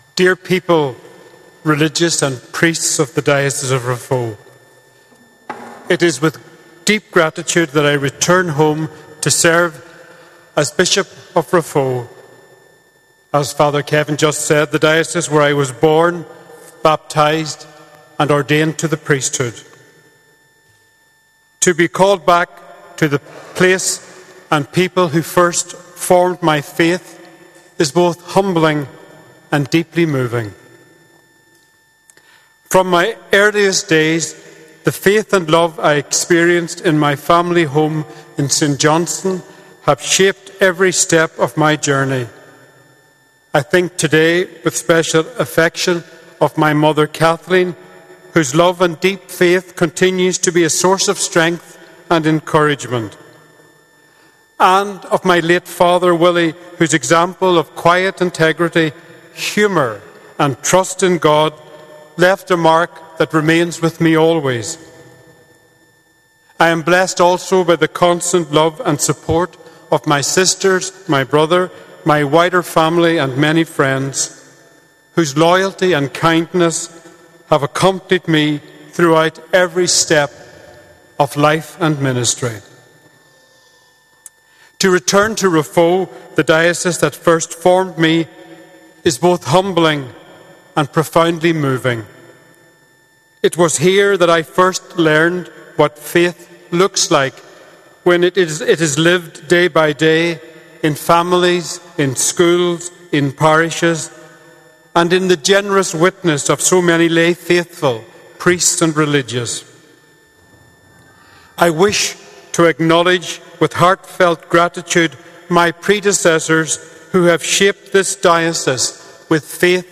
The new Bishop – Elect of Raphoe has told a packed congregation at St Eunan’s Cathedral that there will be changes in how parishes and dioceses are organised into the future.